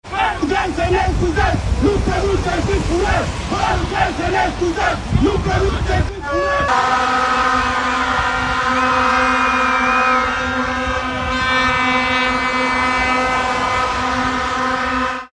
Protest, la această oră, în fața Guvernului.
19feb-15-ambianta-protest-mineri.mp3